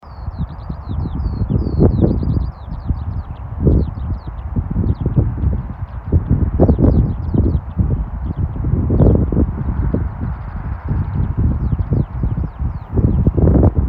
полевой жаворонок, Alauda arvensis
Administratīvā teritorijaRīga
СтатусПоёт